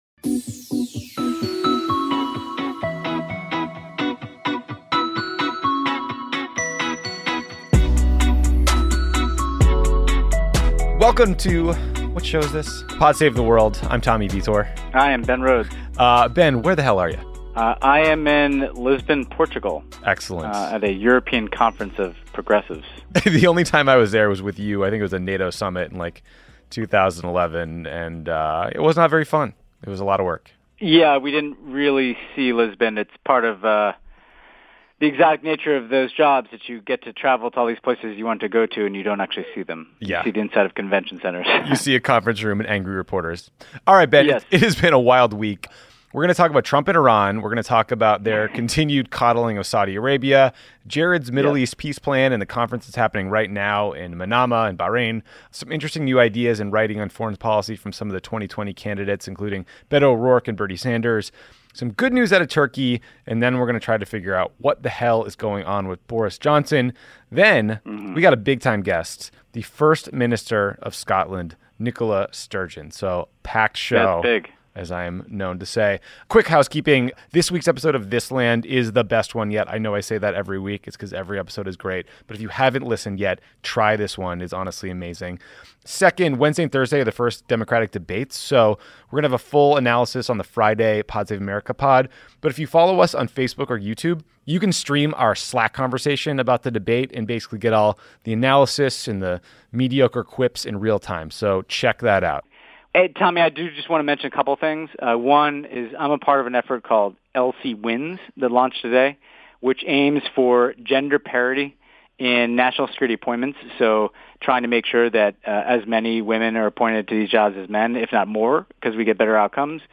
Then, First Minister of Scotland Nicola Sturgeon joins talks with Tommy about Scottish Independence